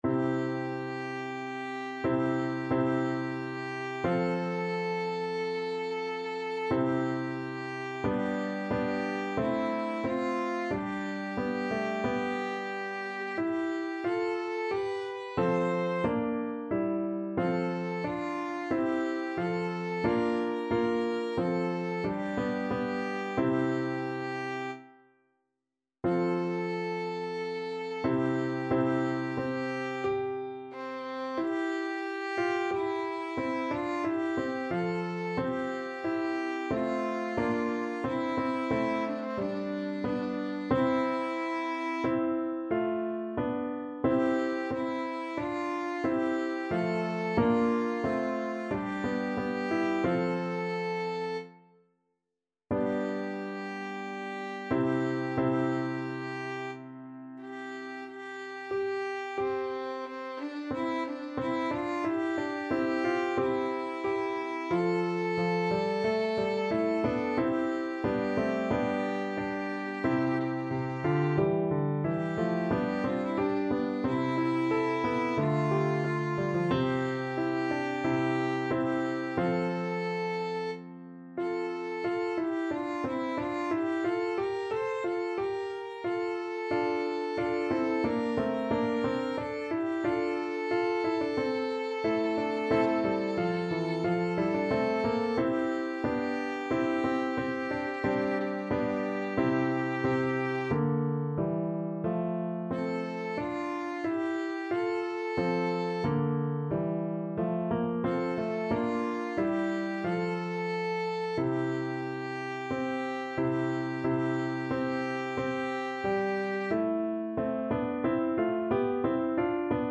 Violin
C major (Sounding Pitch) (View more C major Music for Violin )
4/2 (View more 4/2 Music)
Classical (View more Classical Violin Music)